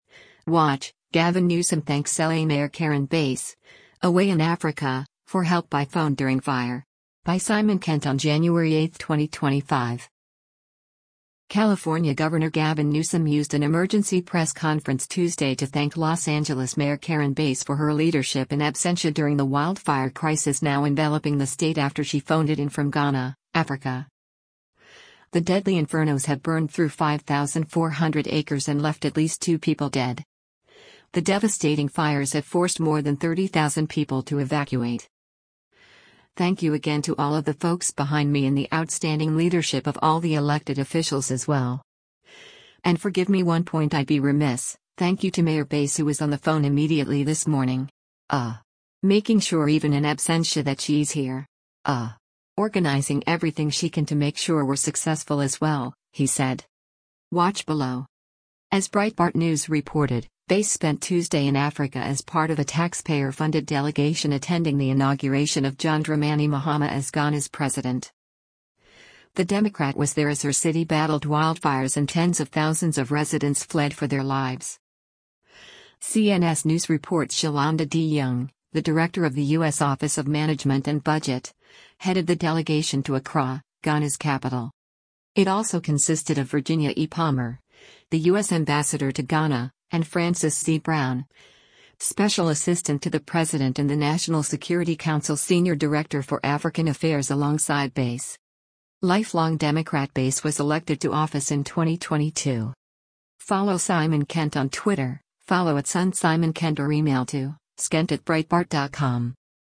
California Governor Gavin Newsom used an emergency press conference Tuesday to thank Los Angeles Mayor Karen Bass for her leadership “in absentia” during the wildfire crisis now enveloping the state after she phoned it in from Ghana, Africa.